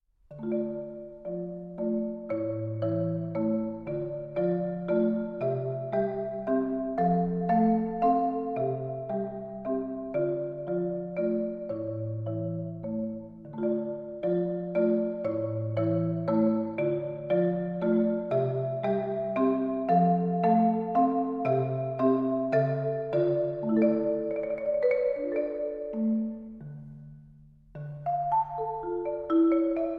Marimba and Vibraphone